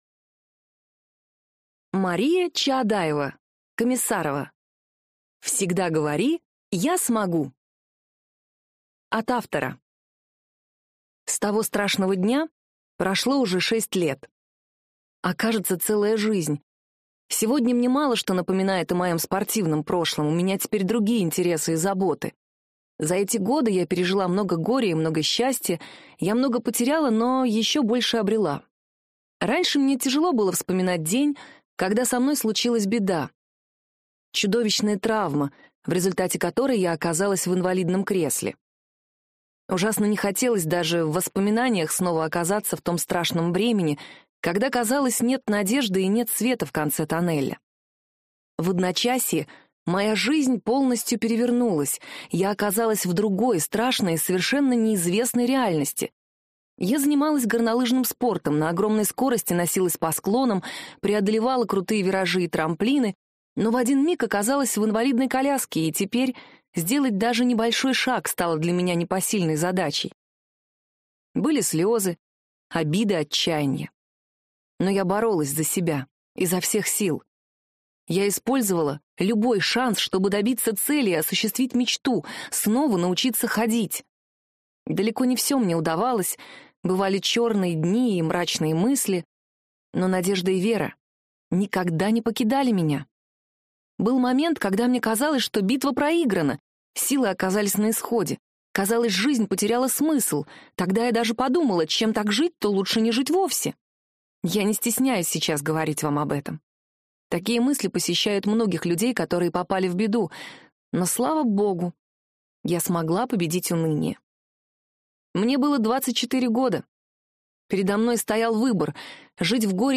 Аудиокнига Всегда говори: Я смогу!